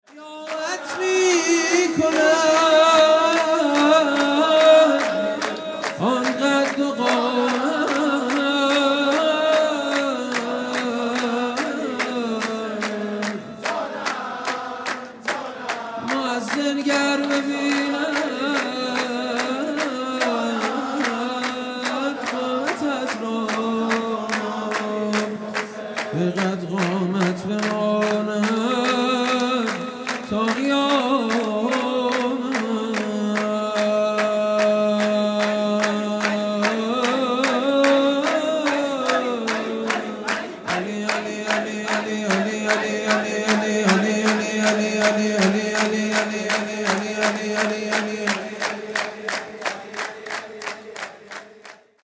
نغمه خوانی